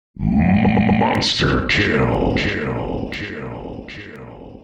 sound_effects
monsterkill.mp3